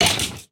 sounds / mob / stray / hurt1.ogg
hurt1.ogg